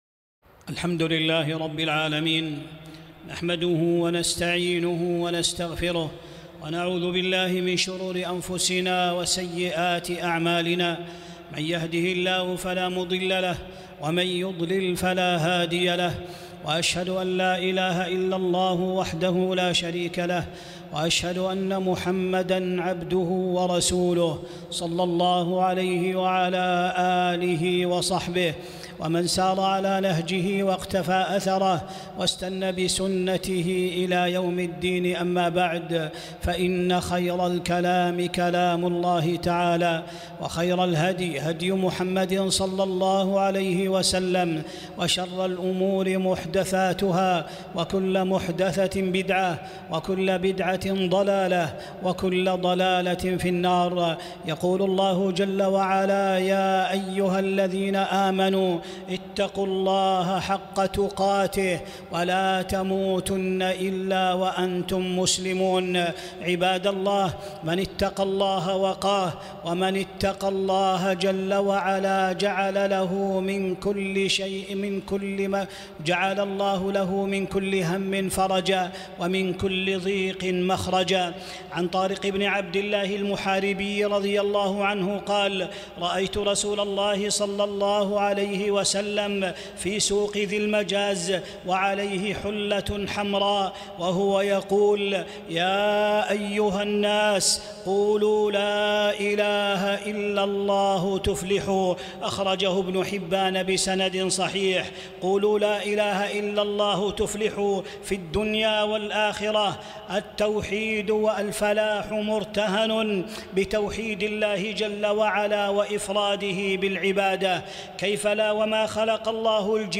خطبة - فضل التوحيد